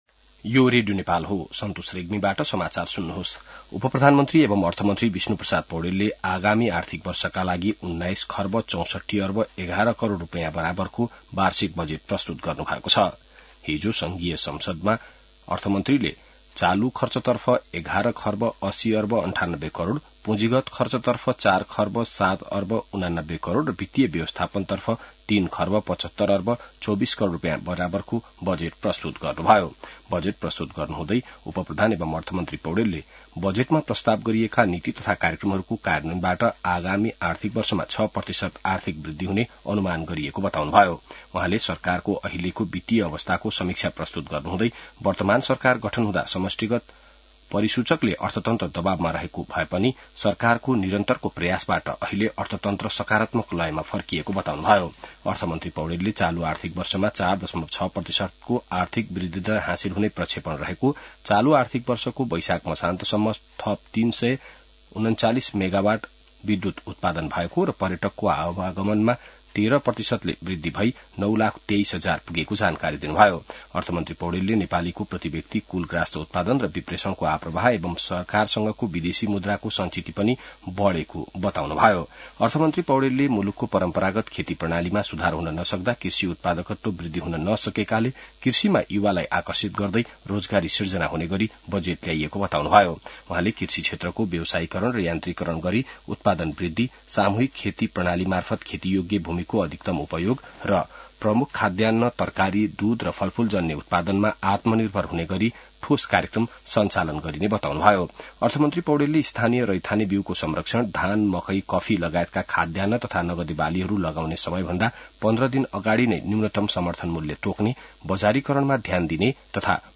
बिहान ६ बजेको नेपाली समाचार : १६ जेठ , २०८२